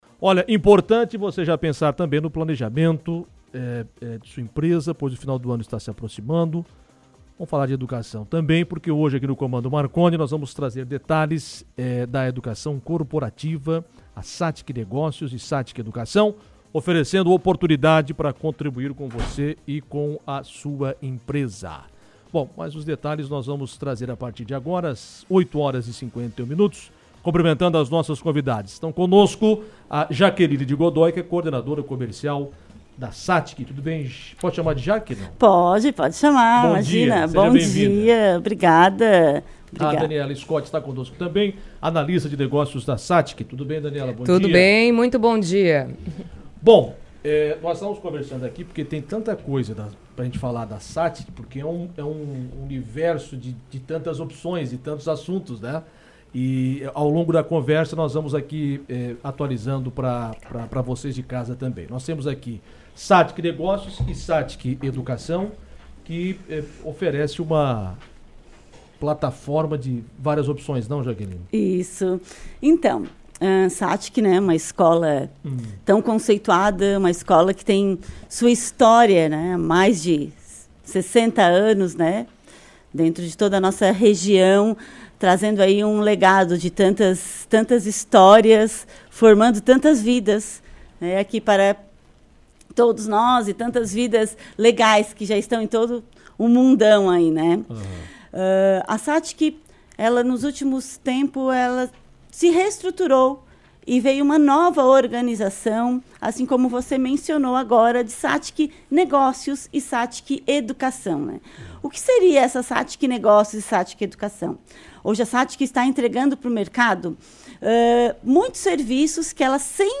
O assunto foi destaque em entrevista no programa Comando Marconi